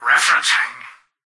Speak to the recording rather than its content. "Referencing" excerpt of the reversed speech found in the Halo 3 Terminals.